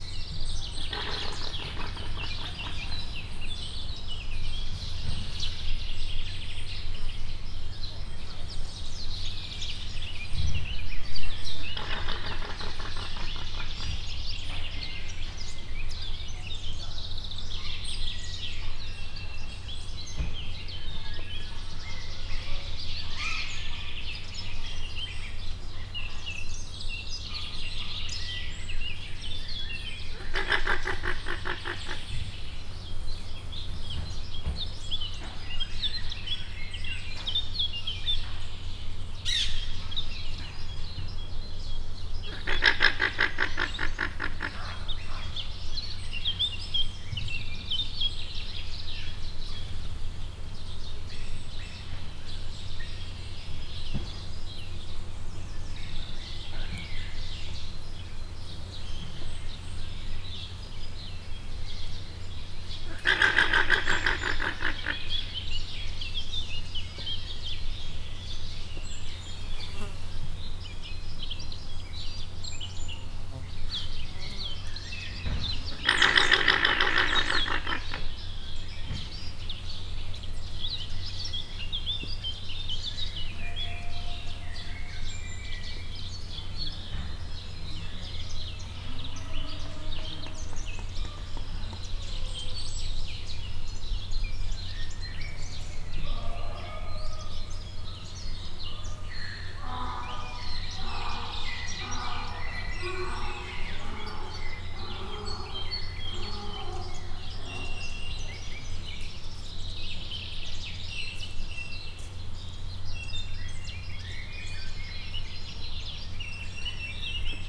atermeszetbenvadoneloguanako_alegvegenkulan_miskolczoo0157.WAV